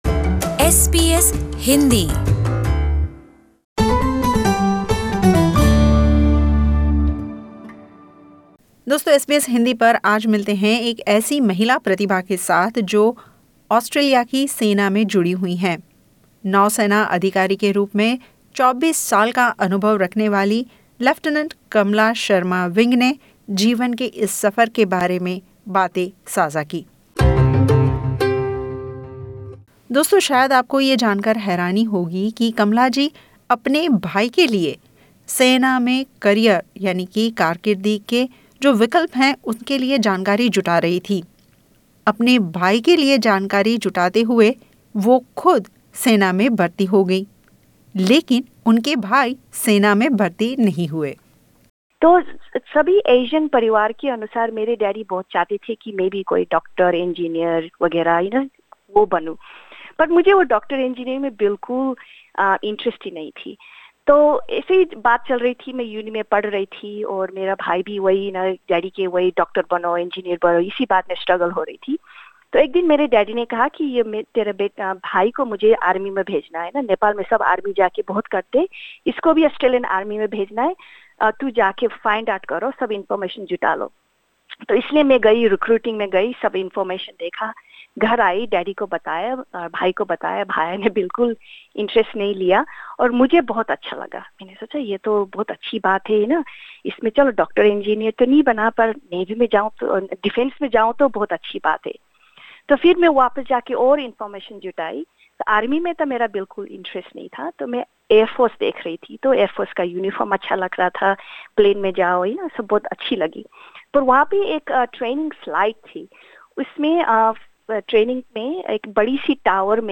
In this exclusive interview